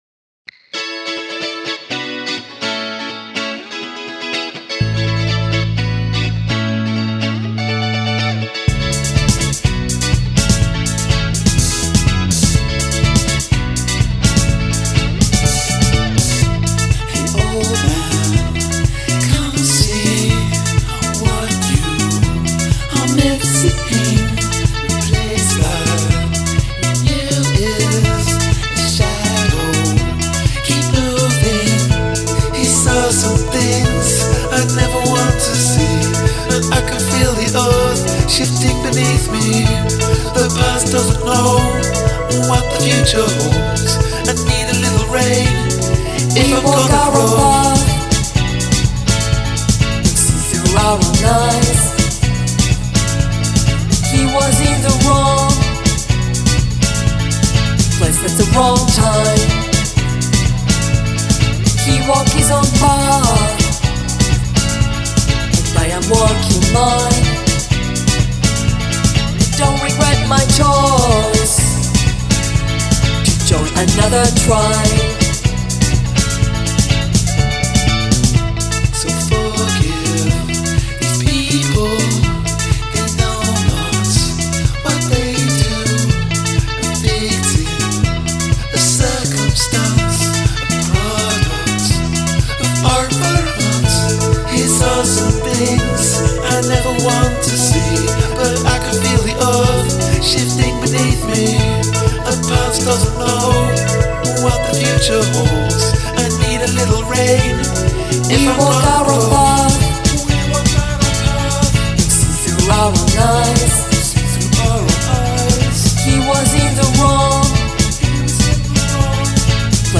this is another one i feel is kind of samey throughout.